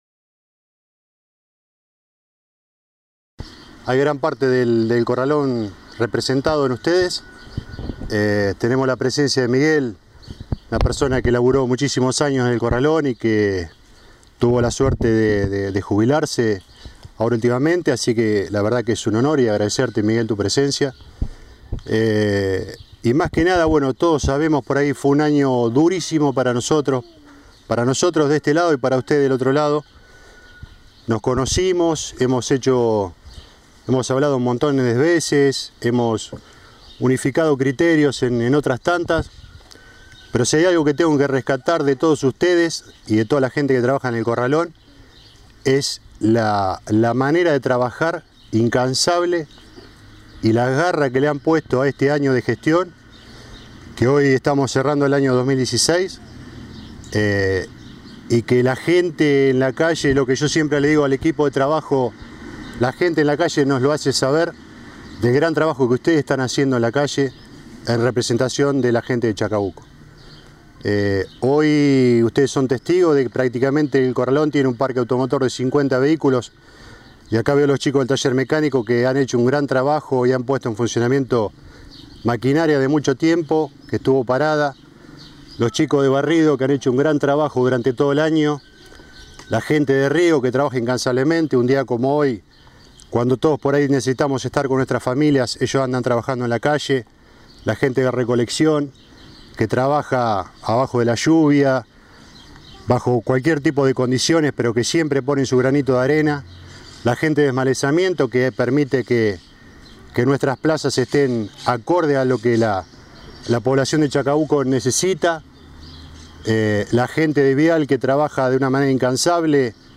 Brindis de fin de año en el Corralón Municipal
Previo a su discurso, el subsecretario de Servicios Públicos, Mario Ferraresi, expresó palabras de agradecimiento hacia todo su personal: » redoblemos los esfuerzos en este 2017 que inicia. Tenemos que seguir trabajando fuertemente por nuestra ciudad. El vecino está agradecido por la labor que venimos realizando; queda muchísimo más pero estoy enormemente agradecido con ustedes».
MARIO-FERRARESI-BRINDIS-CORRALON-2017.mp3